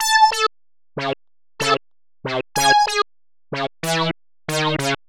Ridin_ Dubs - 303 Synth.wav